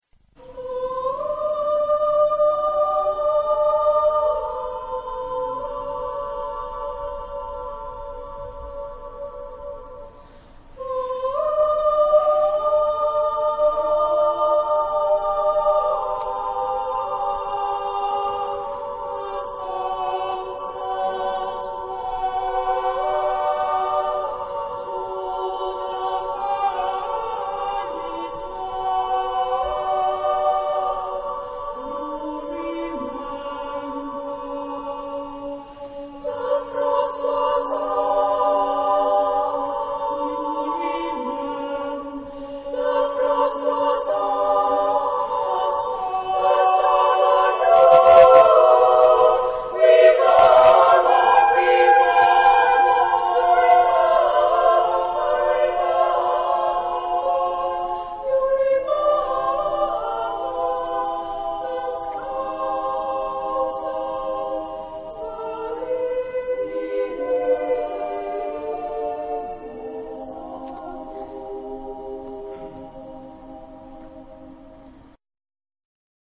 Genre-Style-Form: Lyrical ; Choir ; Cycle
Type of Choir: SSAA  (4 women voices )
Soloist(s): Soprano (1)
Tonality: aleatoric